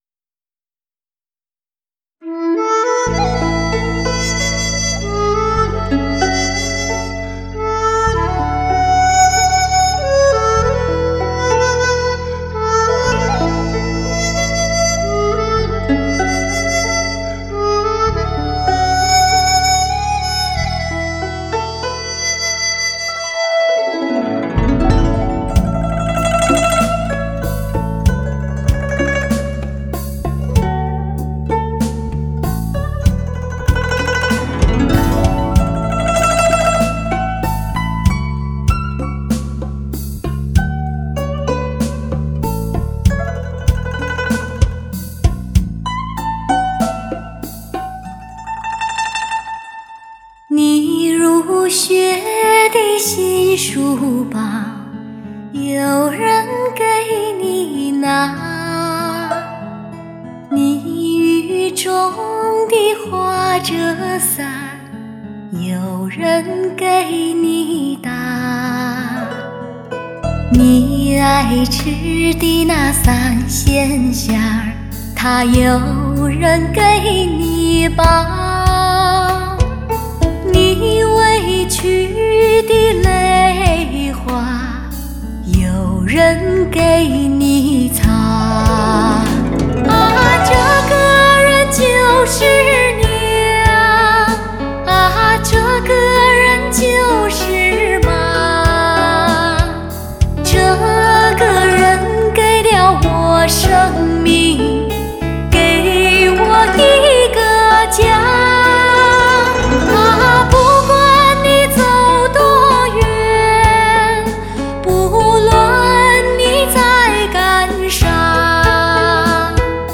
Жанр: Modern Traditional / Chinese pop